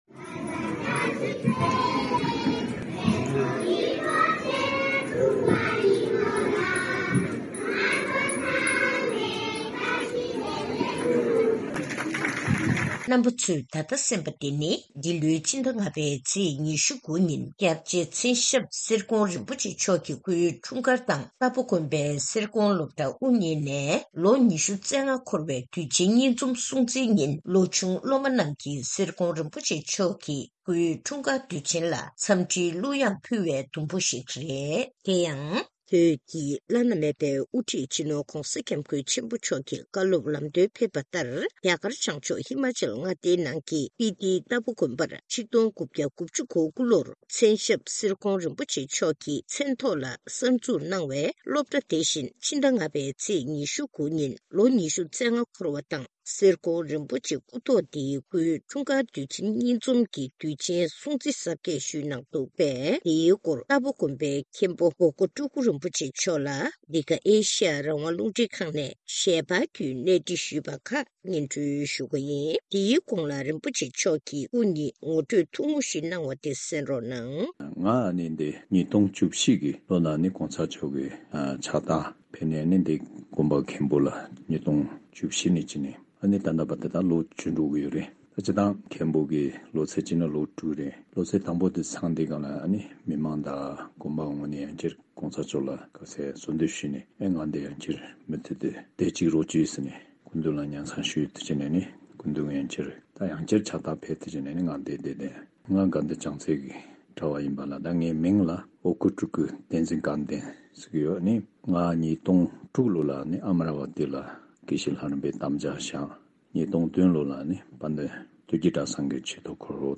གནས་འདྲི་ཞུས་ནས་ཚུལ་ཕྱོགས་བསྒྲིགས་ཞུས་པ་ཞིག་གསན་རོགས་གནང་།།